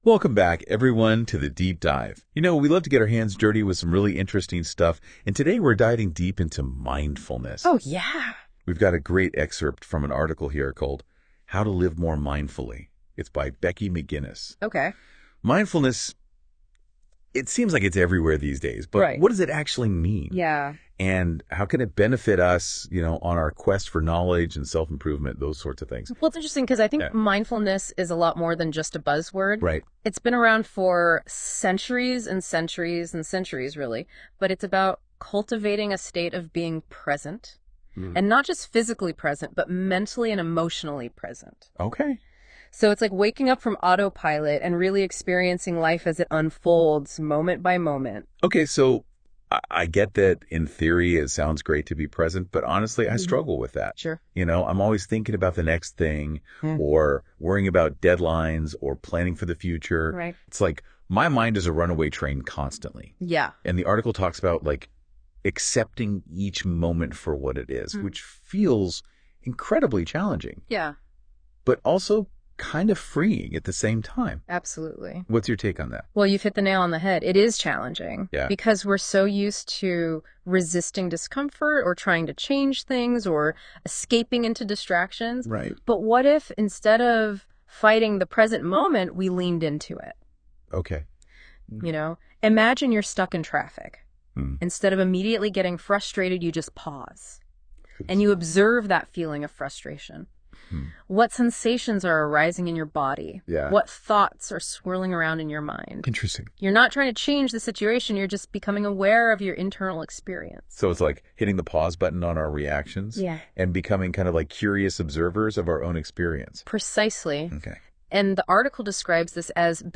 *This audio content was generated using AI.
NotebookLM-Mindfulness_-Building-a-Daily-Practice.m4a